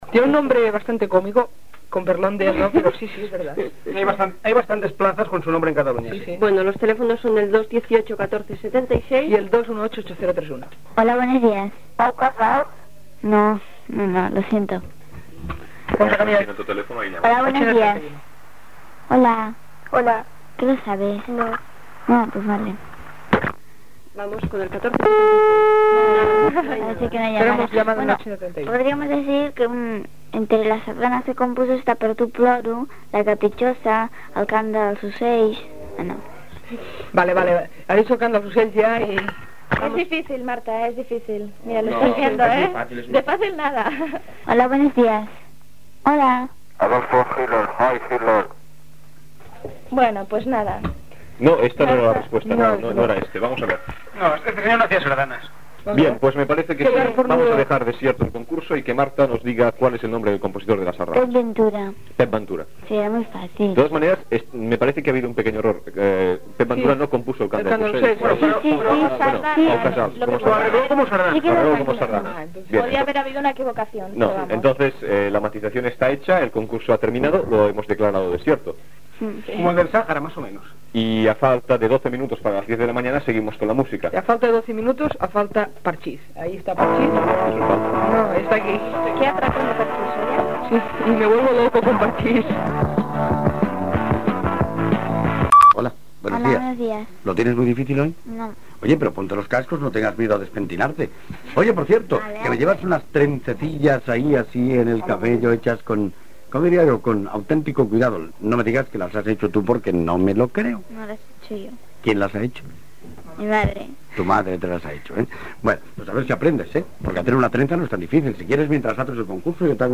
Concurs amb participació telefònica dels oients, presentació d'un tema del grup Parchís. Preguntes sobre els cucs de seda, el barri de la Barceloneta, telèfons de l'emissora, etc.
Infantil-juvenil